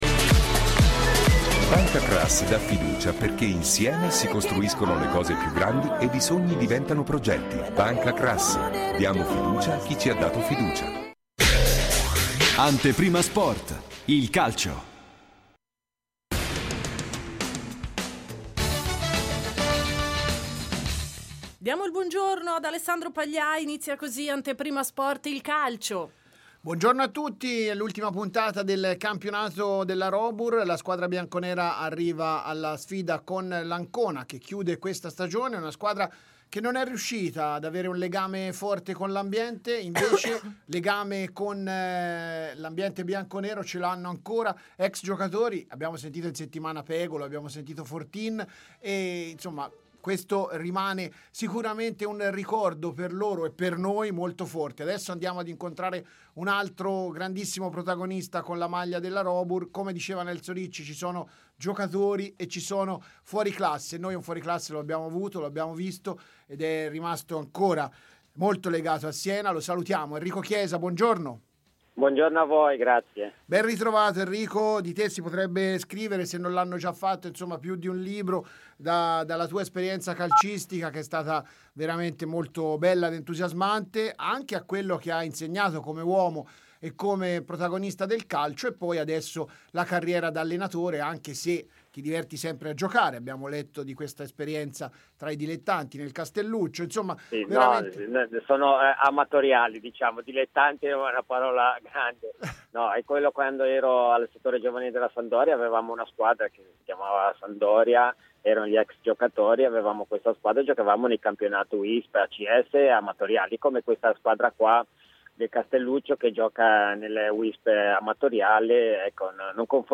il campione ha parlato ai microfoni di Antenna Radio Esse.